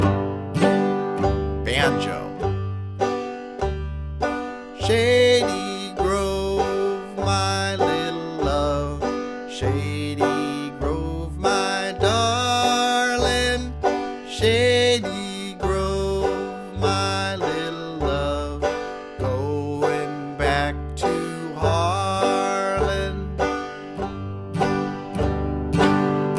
Rhythm: Fiddle